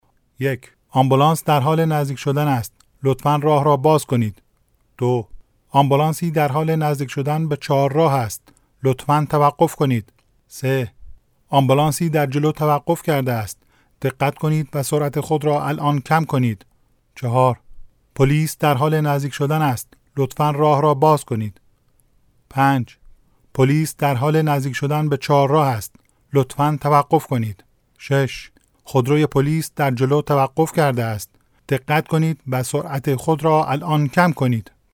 Male
Adult
Elearning